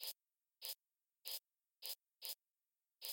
从正弦波创建，应用了一些效果来表现喷雾的声音（健康的主题）.
Tag: 干净 清洁 卫生 喷洒 冲洗 做家务 清洁